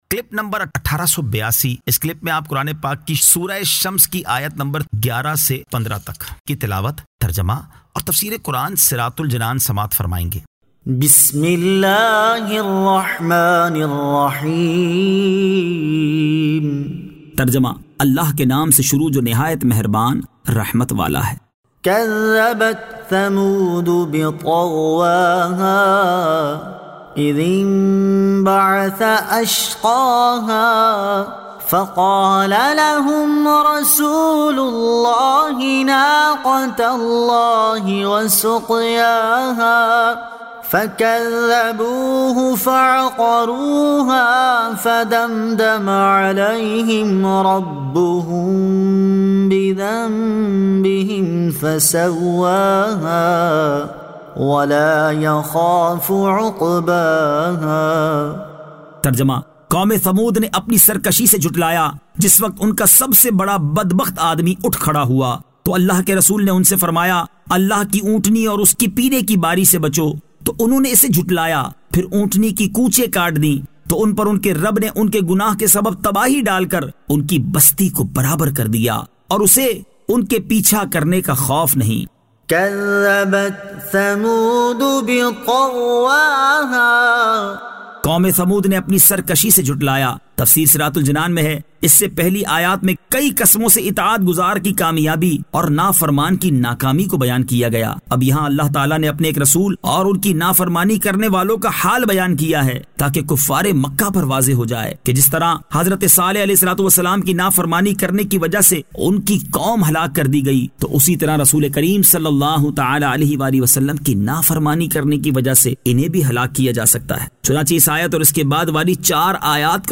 Surah Ash-Shams 11 To 15 Tilawat , Tarjama , Tafseer
2025 MP3 MP4 MP4 Share سُوَّرۃُ الْشَّمْس آیت 11 تا 15 تلاوت ، ترجمہ ، تفسیر ۔